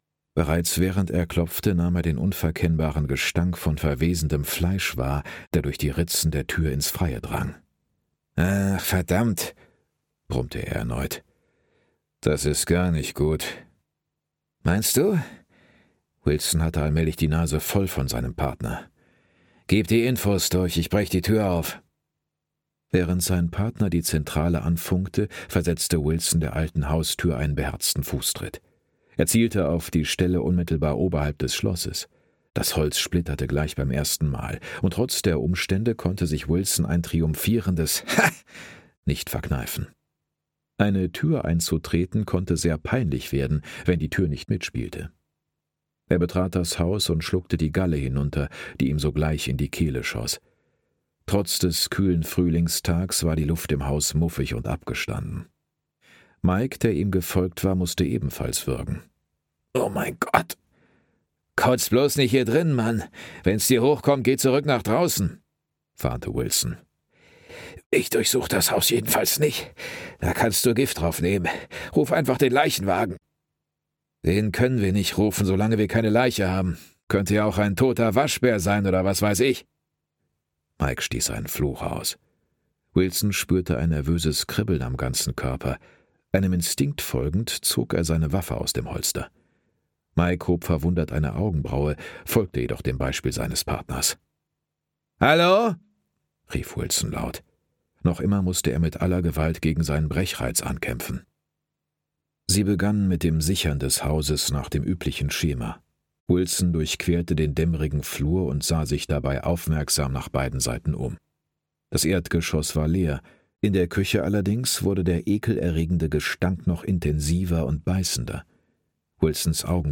Todeskäfig (Ein Sayer-Altair-Thriller 1) - Ellison Cooper - Hörbuch